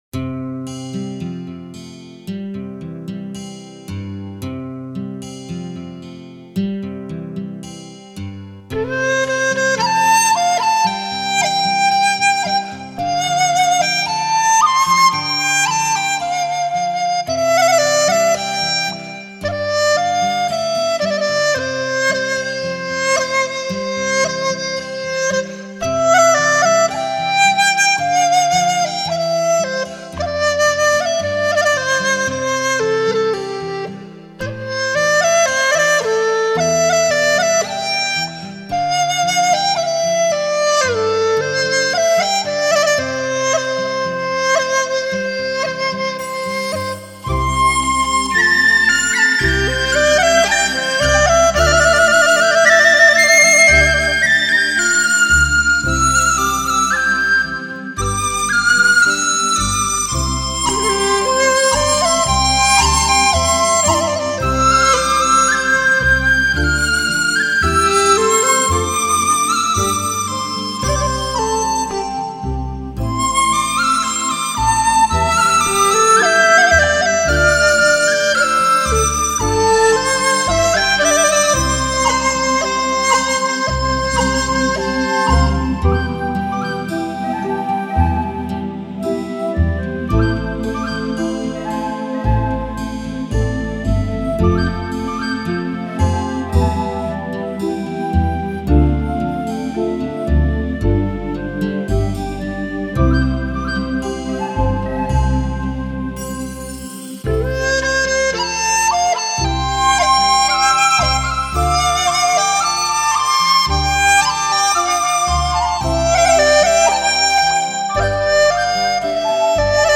以曲笛、梆笛、新笛、口笛、低音笛、木笛为主，辅以领奏、轮奏、二重奏、三重奏加之背景技巧融为一体的全新演绎手法。
中国竹笛就是好听！太喜欢啦！